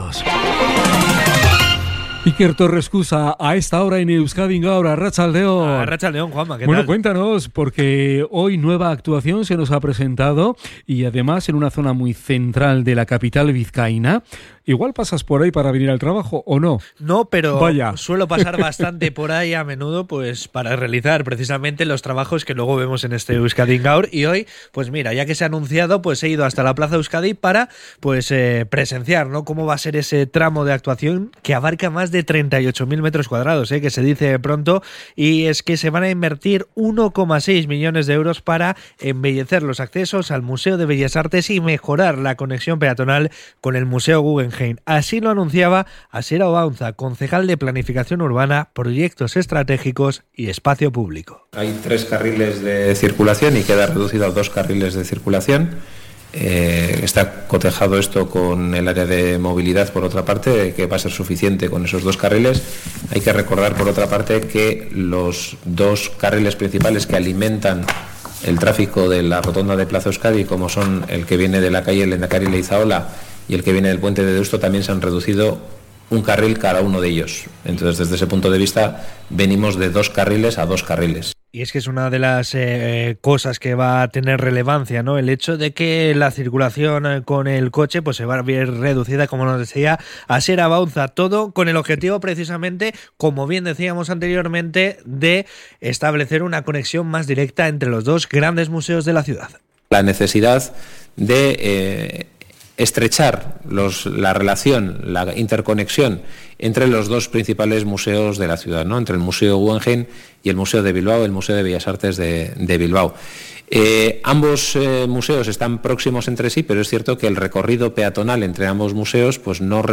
CRONICA-PLAZA-EUSKADI.mp3